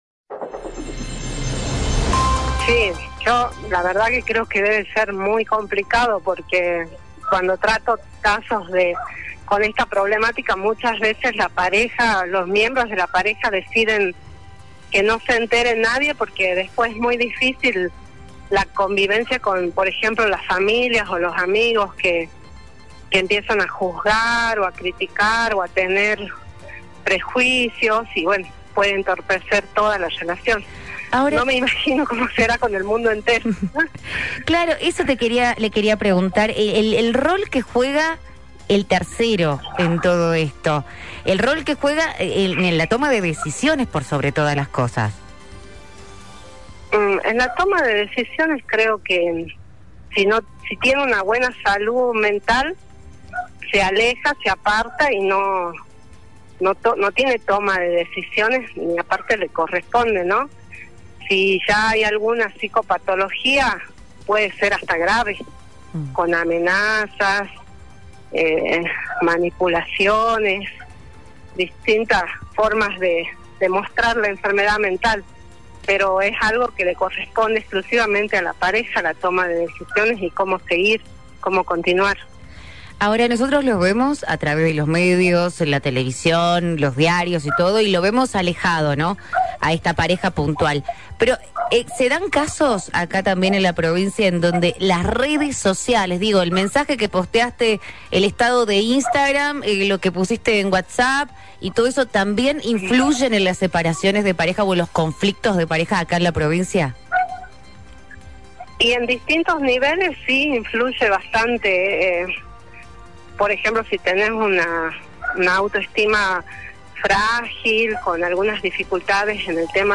Psicóloga jujeña detalla el tema infidelidad